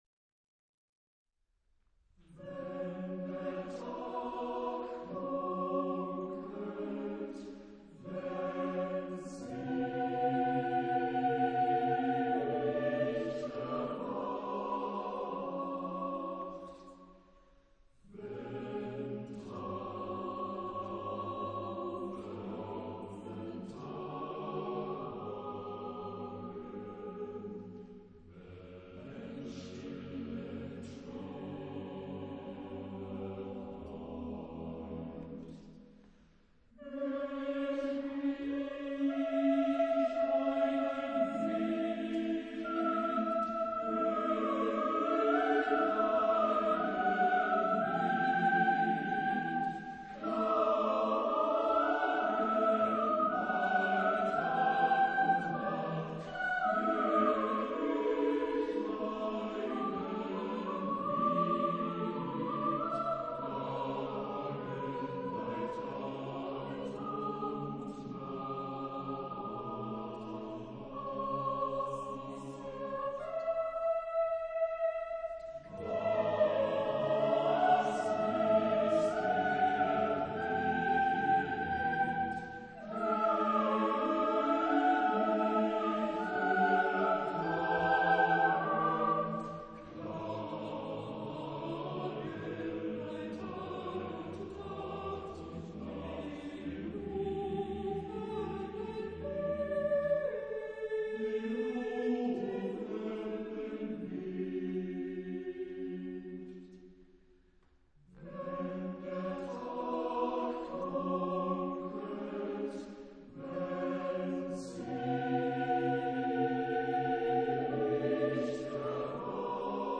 Genre-Stil-Form: weltlich
Chorgattung: SSAATTBB  (8 gemischter Chor Stimmen )